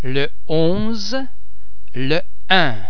Please be mindful of the fact that all the French sounds are produced with greater facial, throat and other phonatory muscle tension than any English sound.
Aspirated: the [e] or [a] of the preceding word is NOTdropped before numerals